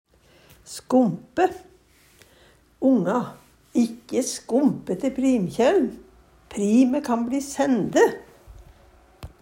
skompe - Numedalsmål (en-US)